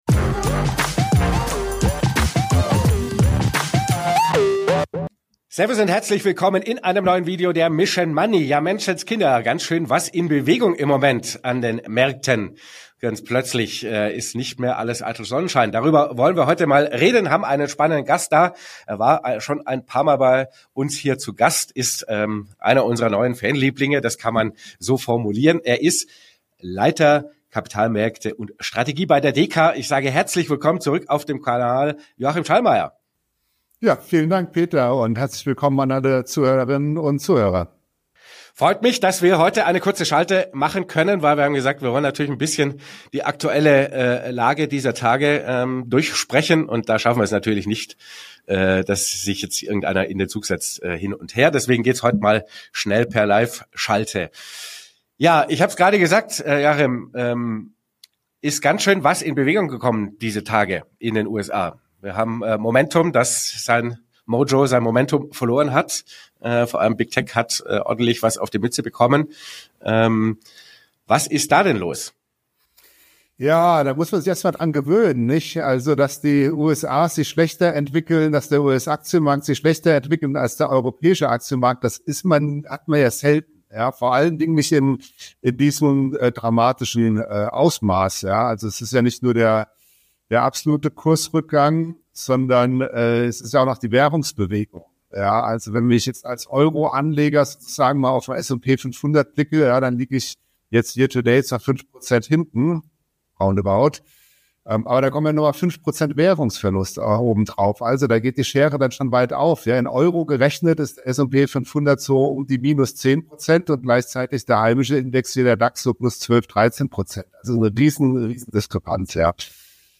Wir befragen für dich jede Woche die besten Finanz- und Wirtschafts-Experten zu aktuellen Themen rund um dein Geld. powered by FOCUS MONEY
Interview